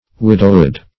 Widowhood \Wid"ow*hood\, n.